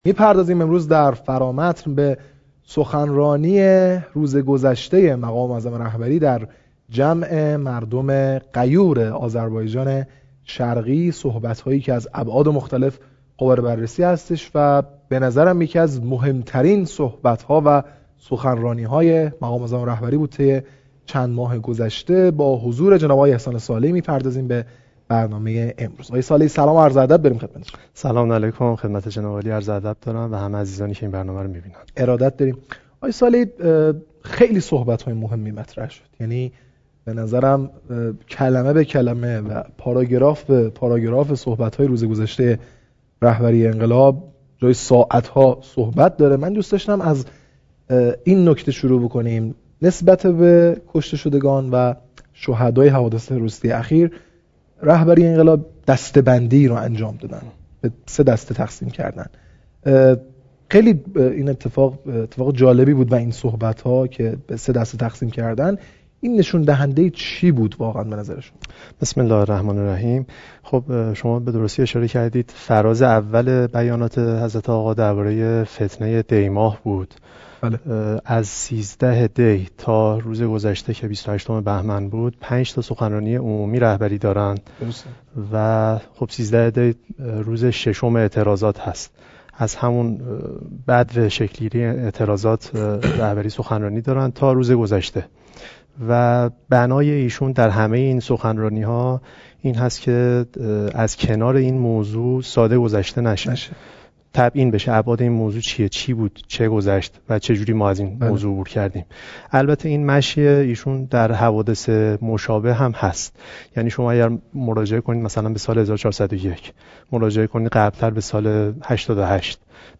رهبر انقلاب در این دیدار در دو محور فتنه دی و مسئله آمریکا و پاسخ به تهدیدها، سخنانی مطرح کردند. در این گفتگو، به سؤالاتی از این قبیل پاسخ داده شده است: